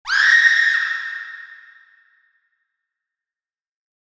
$outh$ide$uicide scream.mp3